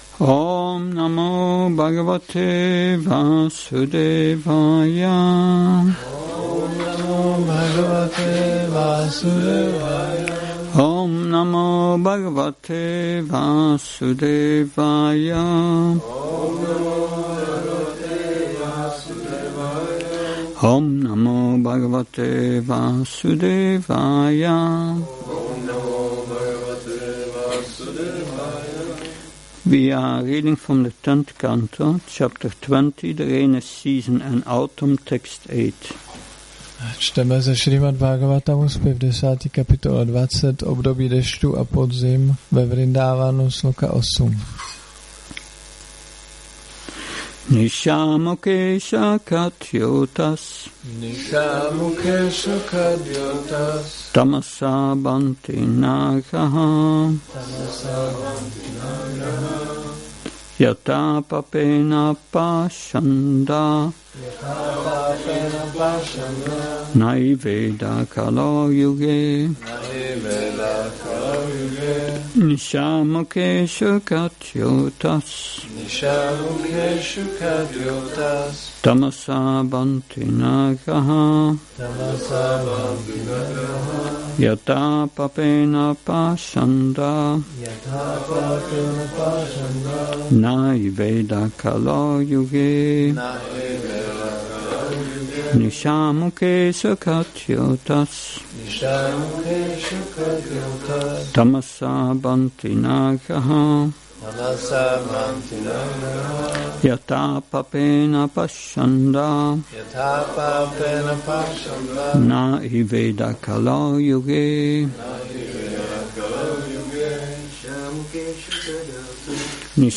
Přednáška SB-10.20.8 – Šrí Šrí Nitái Navadvípačandra mandir